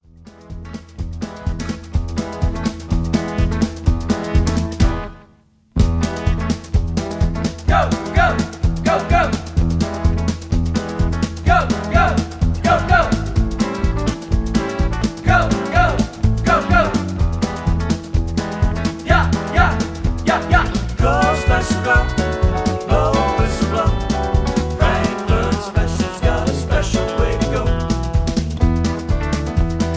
karaoke, studio tracks, sound tracks, backing tracks, rock